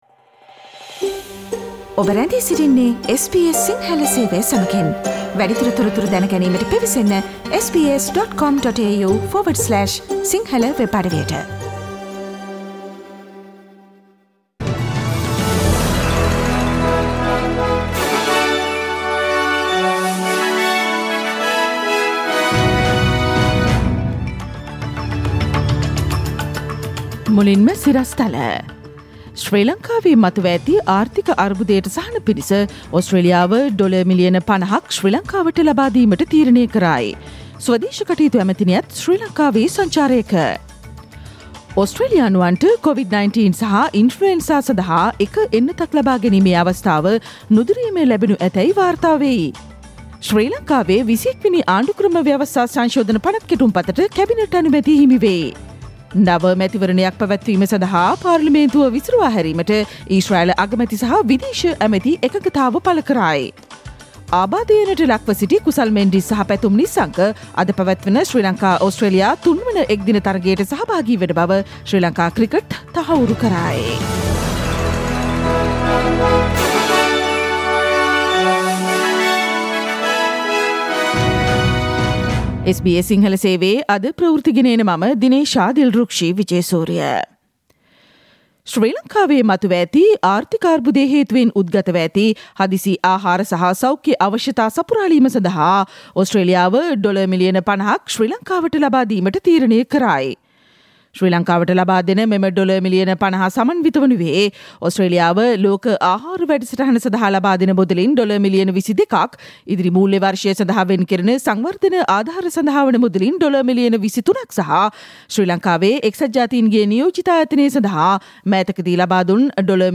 ඉහත චායාරූපය මත ඇති speaker සලකුණ මත ක්ලික් කොට ජූනි 21 වන අඟහරුවාදා SBS සිංහල ගුවන්විදුලි වැඩසටහනේ ප්‍රවෘත්ති ප්‍රකාශයට ඔබට සවන්දිය හැකියි.